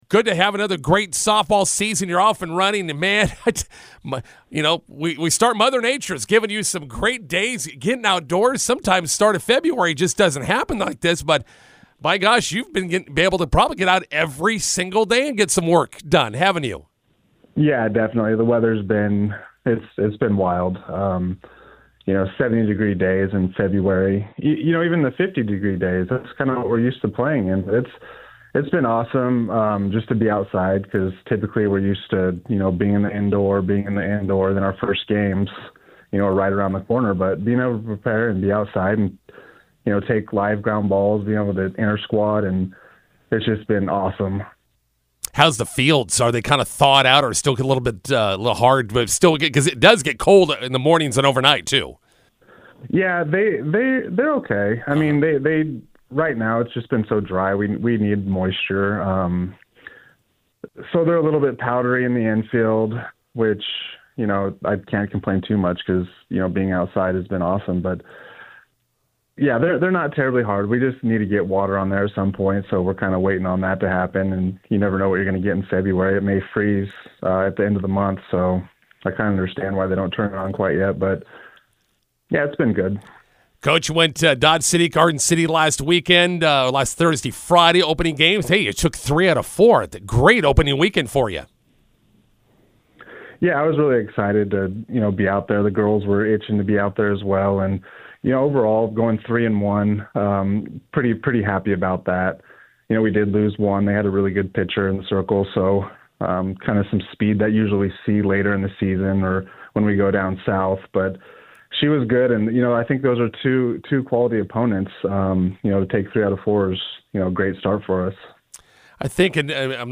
INTERVIEW: McCook Community College softball opens with three wins vs. Dodge/Garden City.